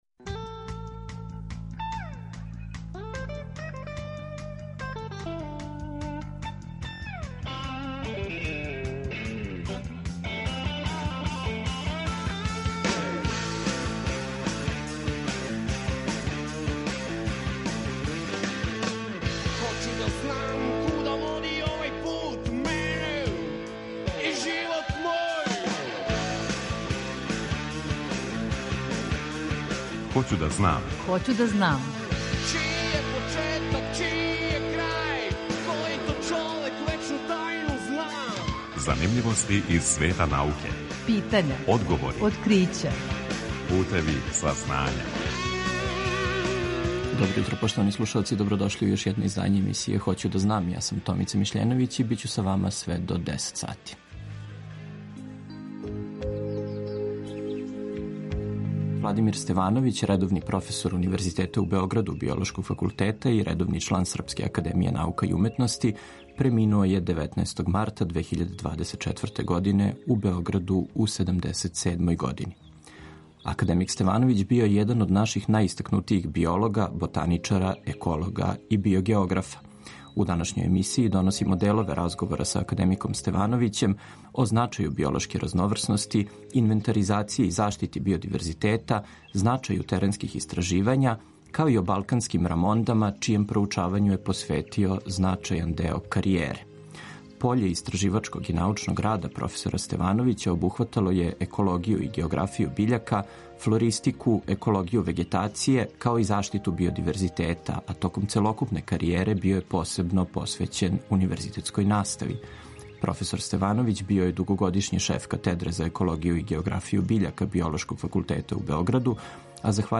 U današnjoj emisiji donosimo delove razgovora